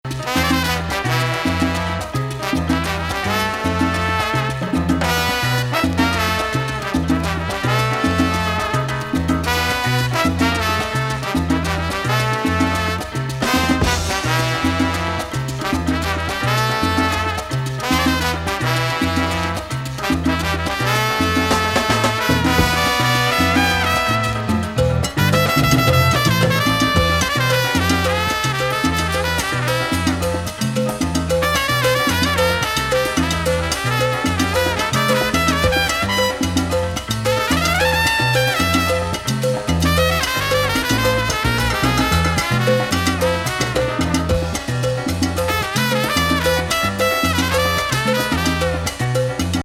78年作!ジャジー&アーバンな洒脱ムード。
歯切れの良いホーン・セクションが華麗。